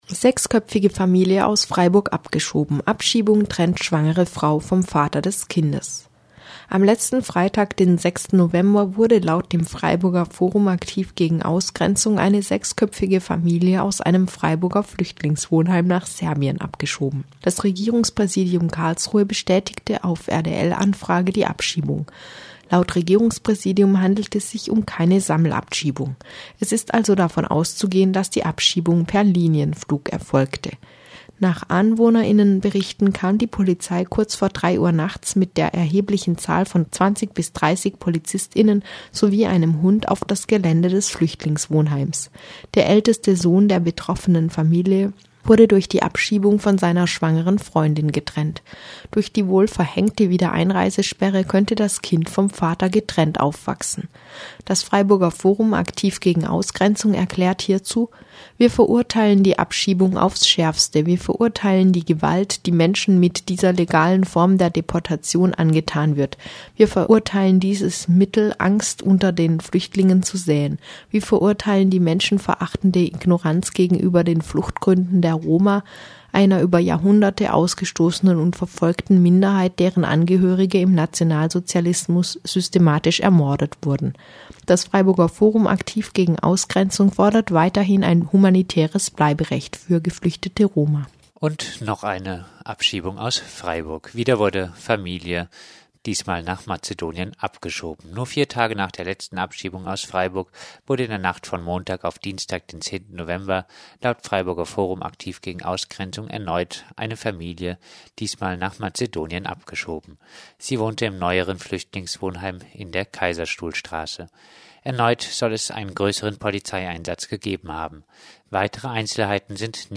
Fokus Südwest Nachrichten, Donnerstag 12. November